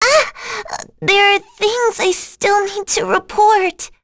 Rehartna-Vox_Dead_b.wav